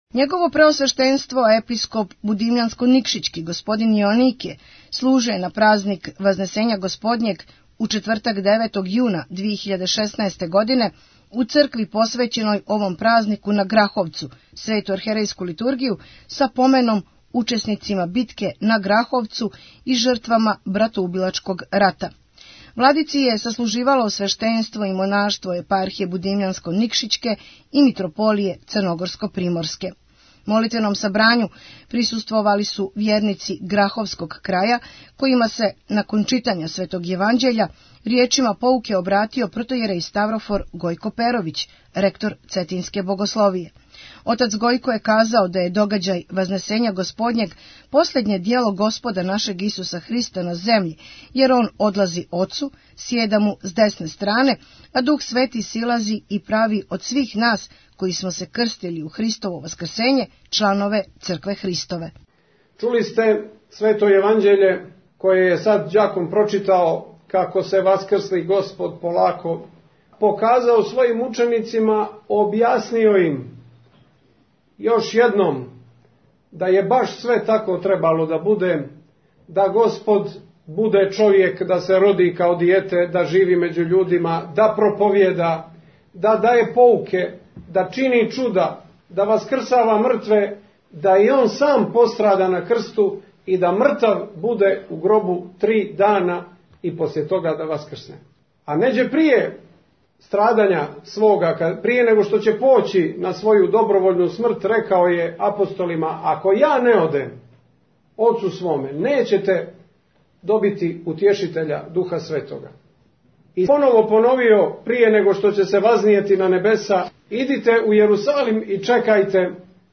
Литургија и помен јунацима битке на Граховцу и жртвама братоубилачког рата
Његово Преосвештенство Епископ будимљанско-никшићки Г. Јоаникије служио је, на празник Вазнесења Господњег - Спасовдан, у четвртак 9. јуна 2016, у цркви посвећеној овом празнику на Граховцу, Свету Архијерејску Литургију са поменом учесницима битке на Граховцу и жртвама братоубилачког рата.
Владици је саслуживало свештенство и монаштво Епархије будимљанско-никшићке и Митрополије црногорско-приморске.